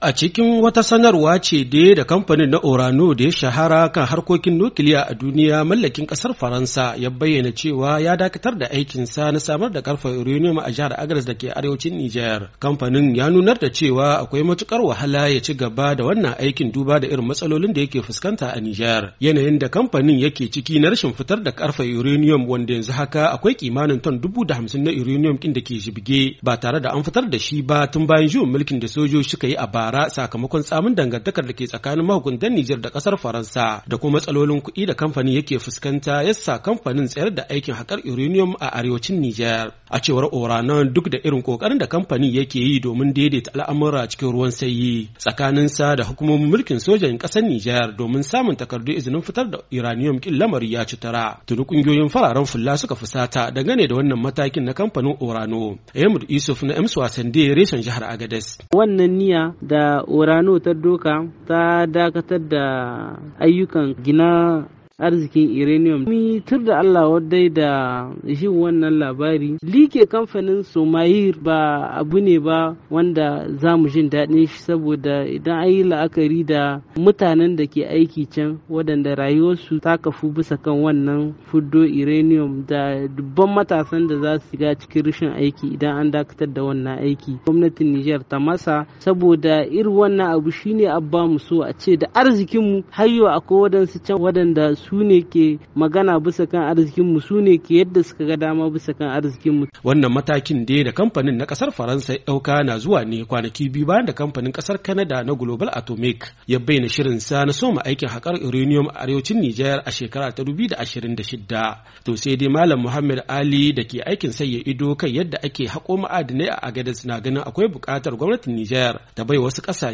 Agadez, Nijar —
RAHOTON DAKATAR DA AIKIN KANPANIN ORANO